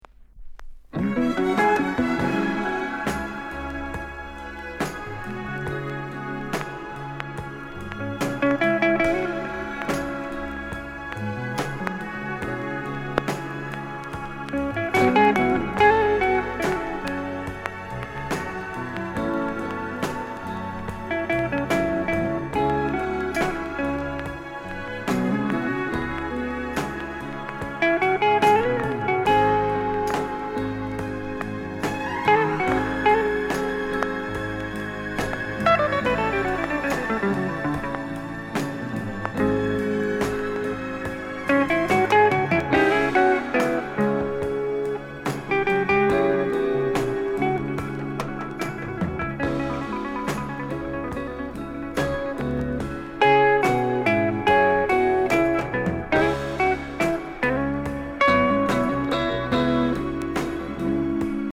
NICE GUITAR INST